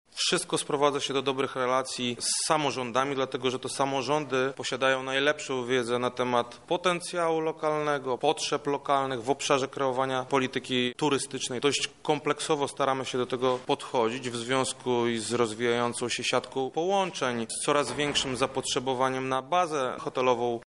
– mówi Krzysztof Komorski, zastępca prezydenta Lublina.